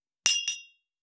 265,乾杯,アルコール,バー,お洒落,モダン,カクテルグラス,ショットグラス,おちょこ,テキーラ,シャンパングラス,カチン,チン,カン,ゴクゴク,プハー,シュワシュワ,コポコポ,ドボドボ,トクトク,カラカラ,ガシャーン,クイッ,ジュワッ,パチパチ,ドン,ザブン,
コップ